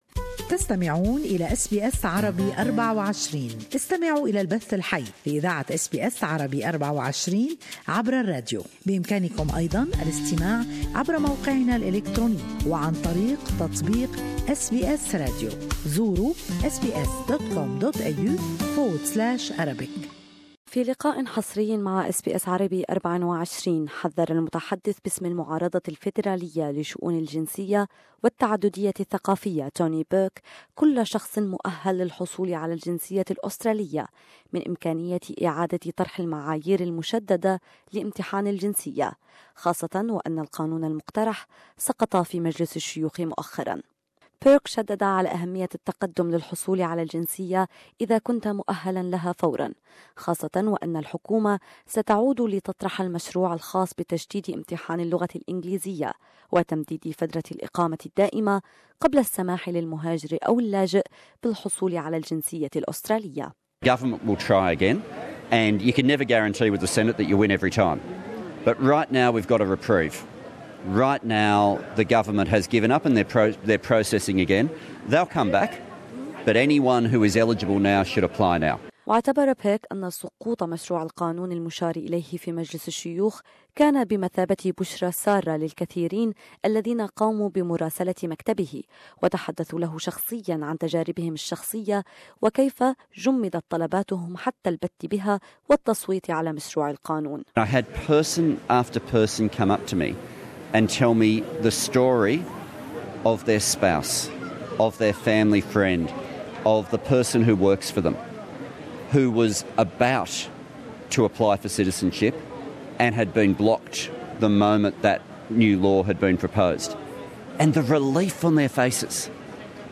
Tony Burke speaks exclusivly to SBS Arabic24.